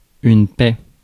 Ääntäminen
Synonyymit concorde pacte rapatriage Ääntäminen France: IPA: [pɛ] Haettu sana löytyi näillä lähdekielillä: ranska Käännös Ääninäyte Substantiivit 1. peace US UK 2. rest US Suku: f .